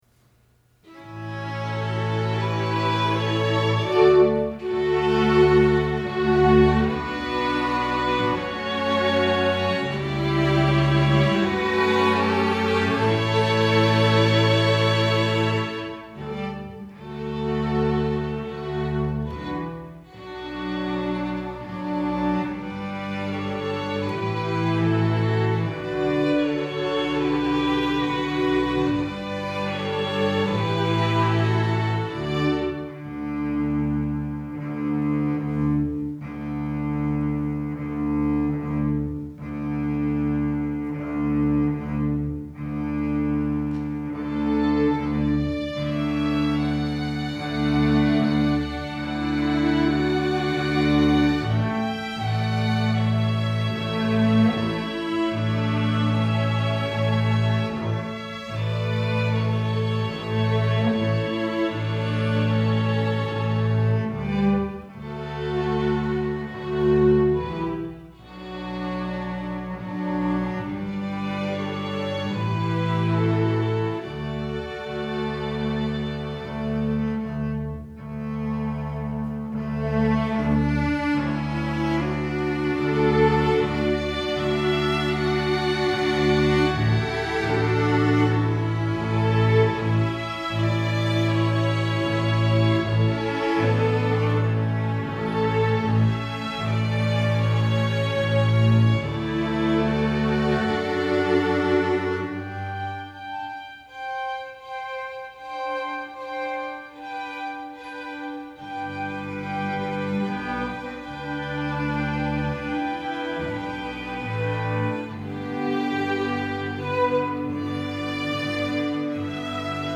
Genre: String Orchestra
Violin I
Violin II
Viola
Cello
Double Bass